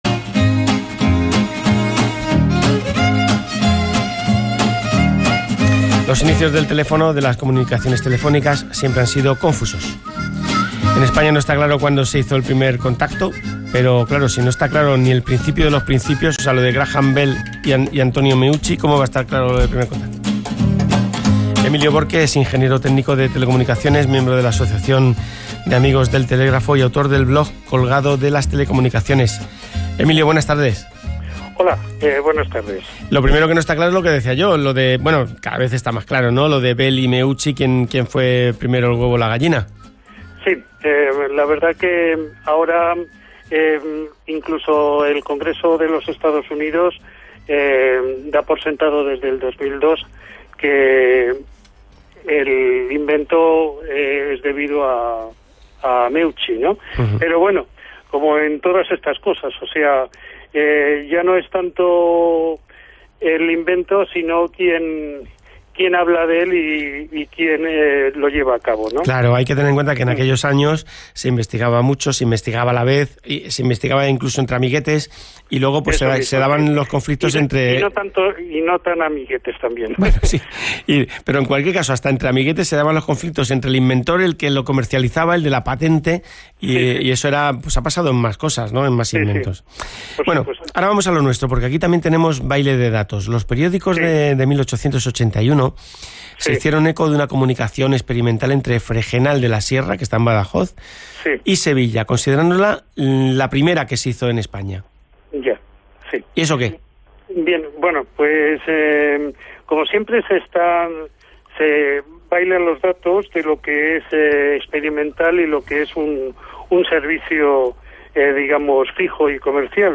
Aquí tenéis la entrevista completa: